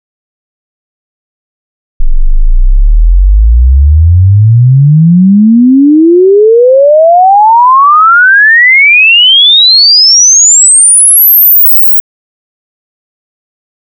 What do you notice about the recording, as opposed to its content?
sid-measurements - SID chip measurements